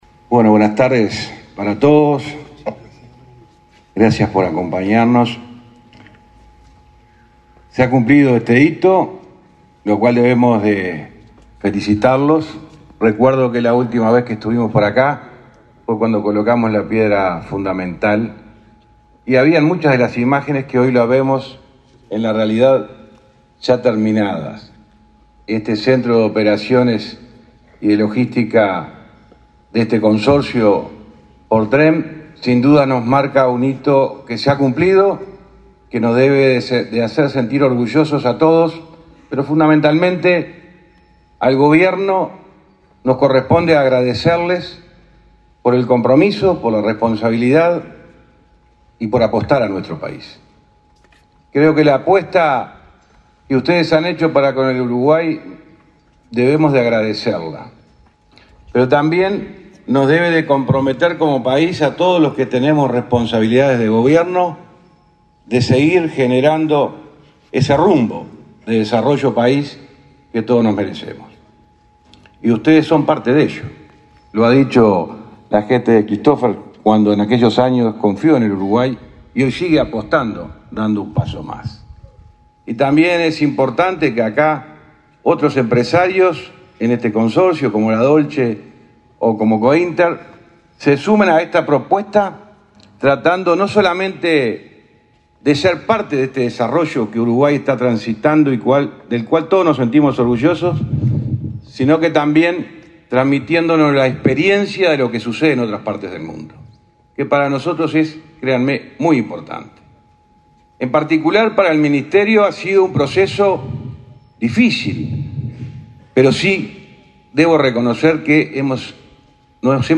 Palabras del ministro de Transporte y Obras Públicas, José Luis Falero
Con la presencia del presidente de la República, Luis Lacalle Pou, y del secretario de la Presidencia, Álvaro Delgado, este 6 de octubre, se inauguró el centro de operaciones y mantenimiento de PorTren, ferrocarril que unirá Montevideo con Paso de los Toros. Disertó el ministro de Transporte y Obras Públicas, José Luis Falero.